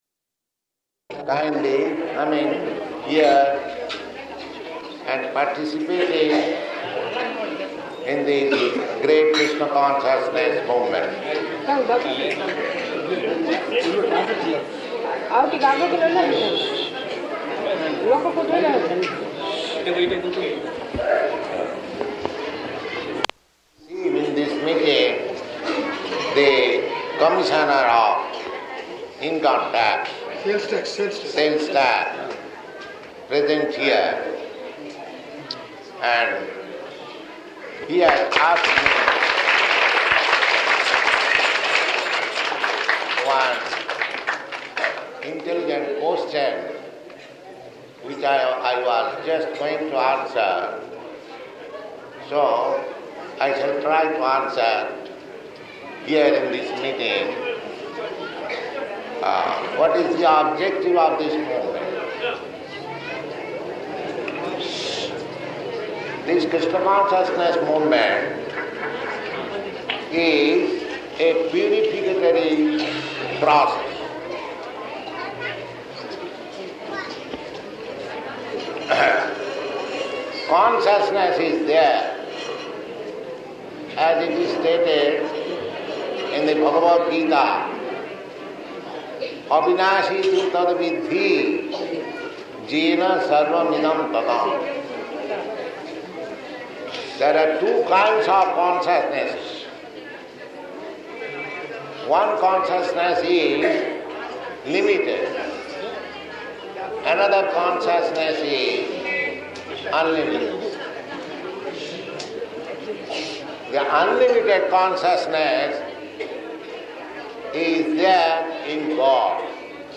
Lecture at Śrī Caitanya Maṭha
Type: Lectures and Addresses
Location: Visakhapatnam
[audience talking loudly in background throughout]